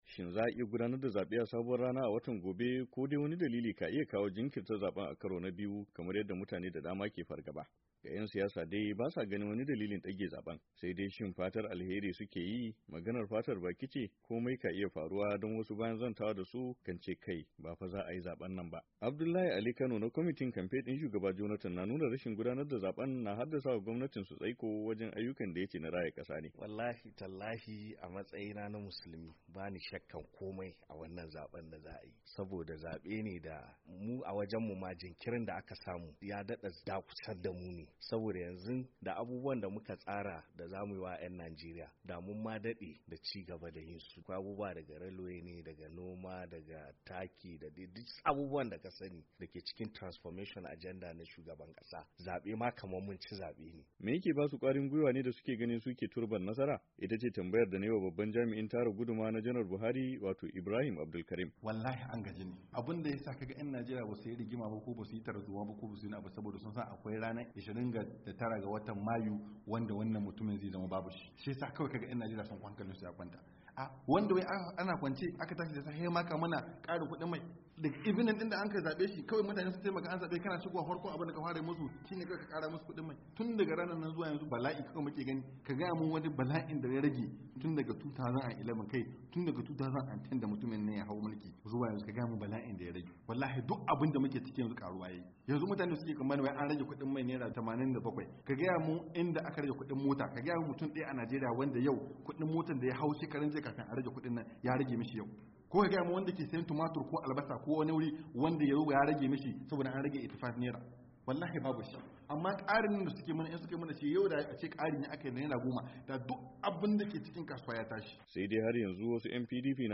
WASHINGTON, DC —